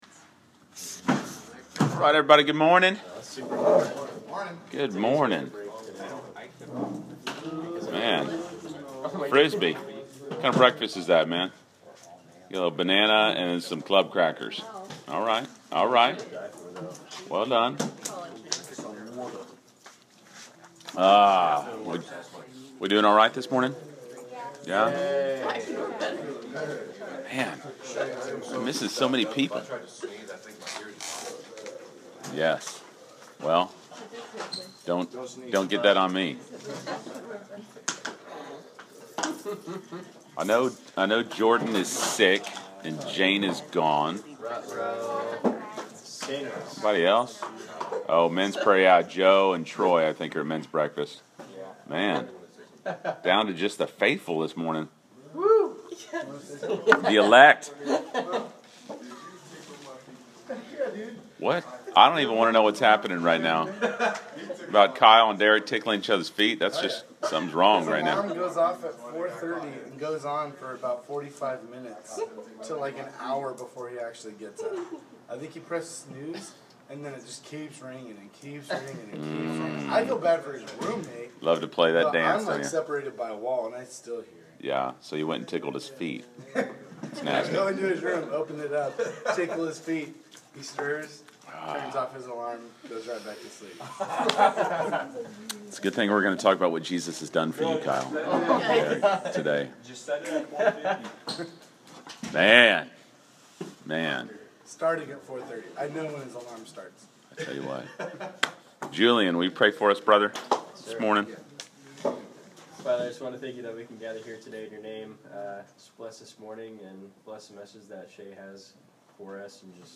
Class Session Audio March 14